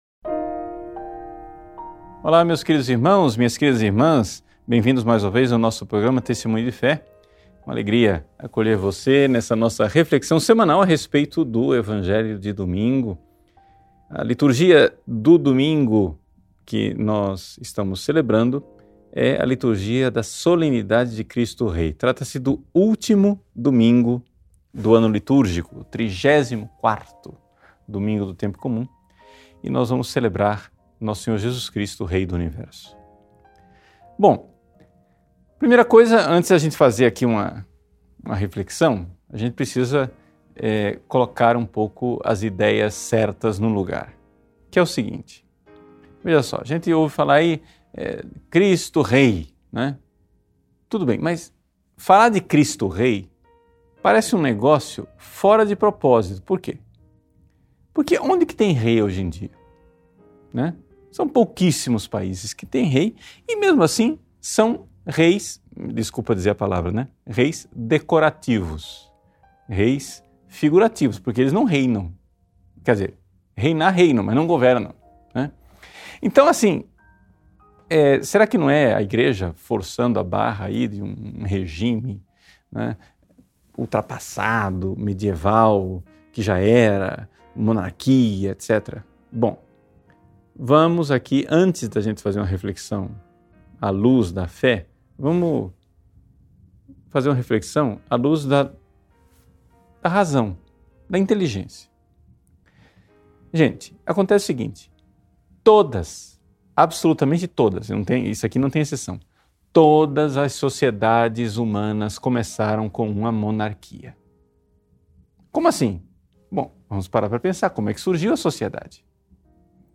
Meditação.